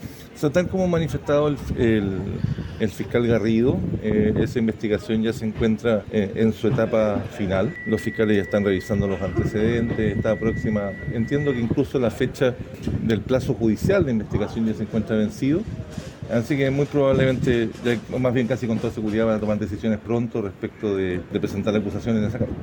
Por su parte, el fiscal nacional, Ángel Valencia, detalló los avances de la investigación, afirmando que está en su fase final.